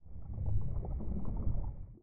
Add footsteps for water - swimming sounds.
I've taken a single, longer audio sample and extracted 3 samples of 2.0 length, and gave them all a 0.5 sec fade-in and fade-out.
This combined with the rate of footstep sounds results in a randomly changing underwater sound that blends in and out somewhat nicely.
default_water_footstep.3.ogg